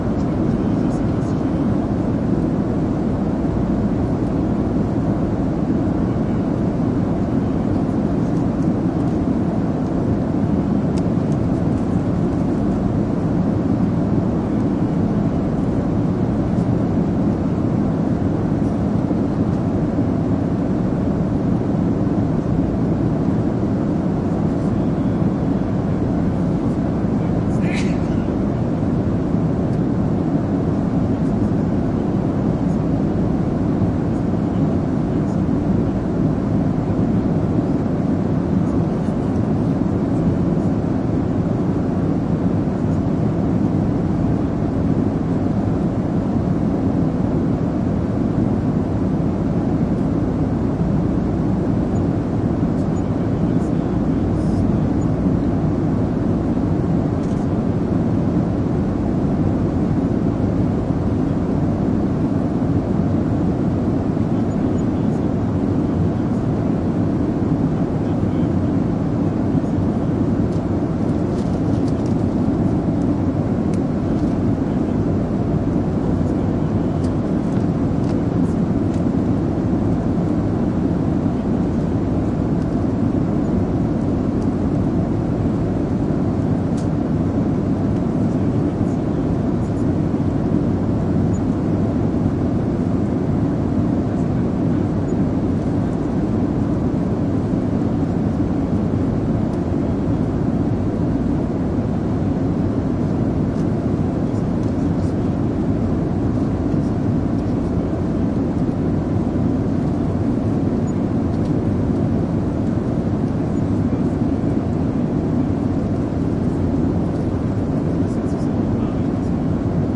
Takeoff from inside plane
描述：Inside an airplane cabin from the time we depart the gate to the time we're well in the air.
标签： takeoff airplanecabin airplane engine
声道立体声